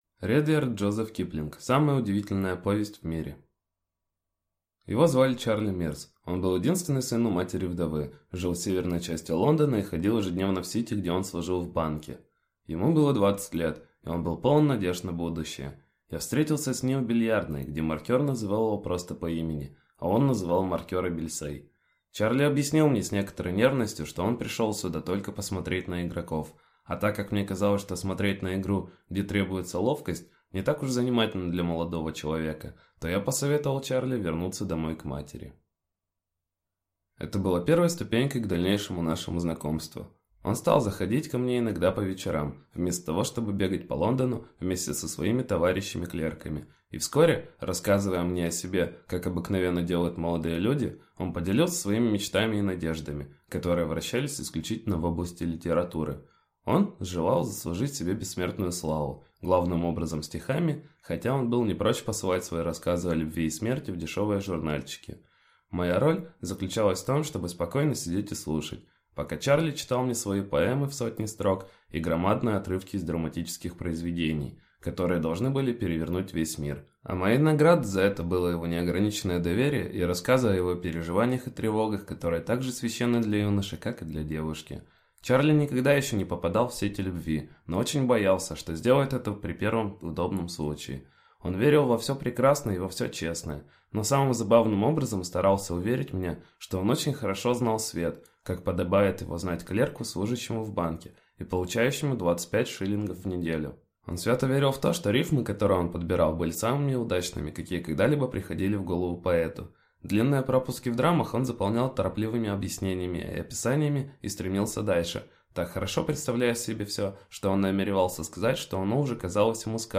Аудиокнига Самая удивительная повесть в мире | Библиотека аудиокниг